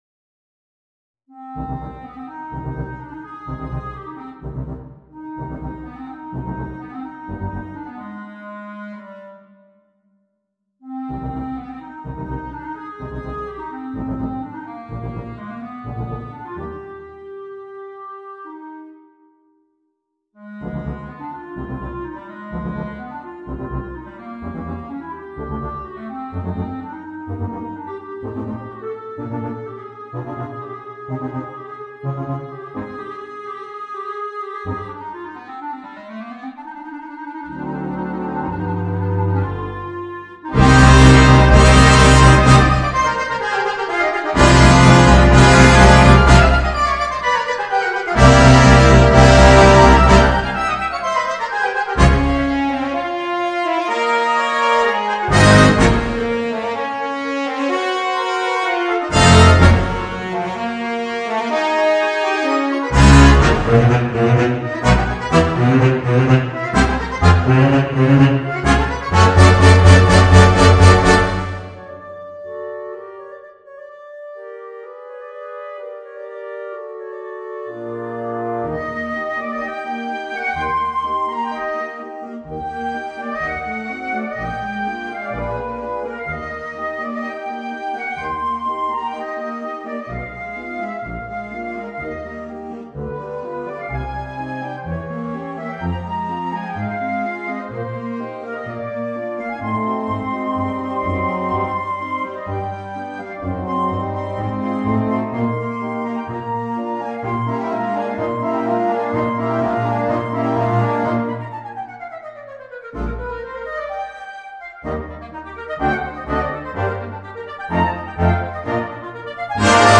Orchestre à Vent